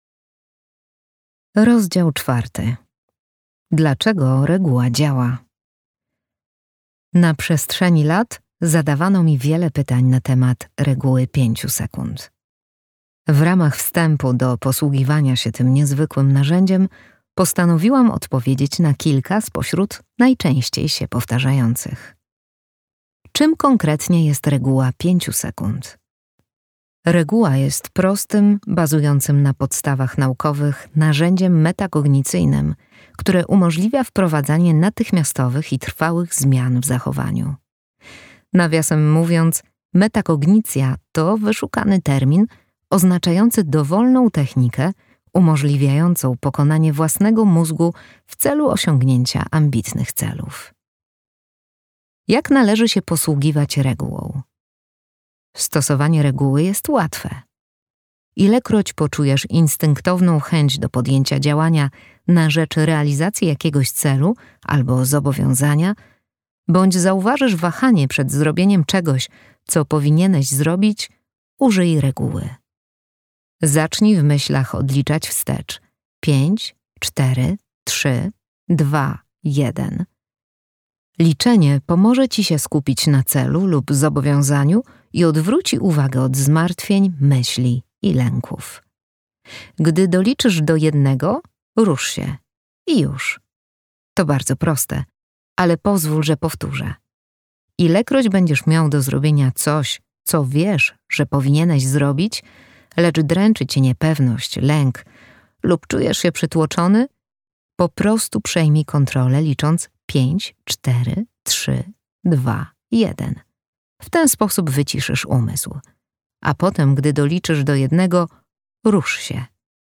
fragment książki:
Audiobook MP3